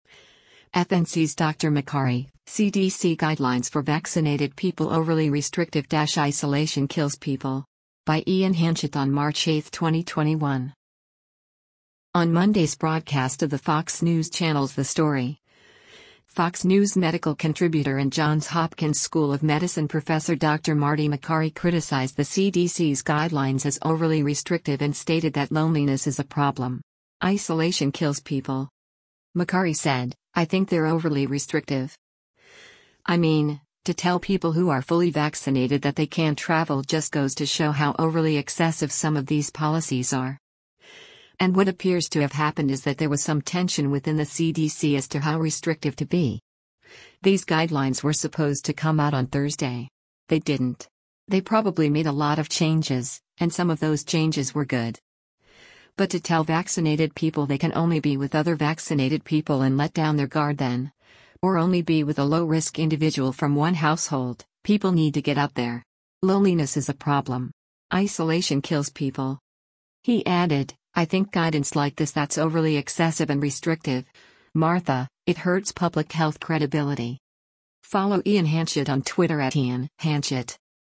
On Monday’s broadcast of the Fox News Channel’s “The Story,” Fox News Medical Contributor and Johns Hopkins School of Medicine Professor Dr. Marty Makary criticized the CDC’s guidelines as “overly restrictive” and stated that “Loneliness is a problem. Isolation kills people.”